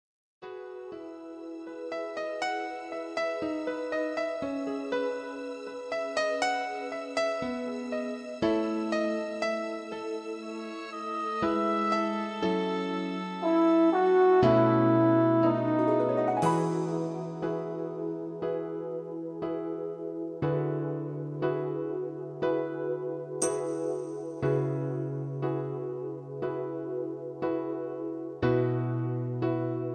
karaoke , backing tracks